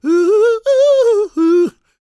GOSPMALE187.wav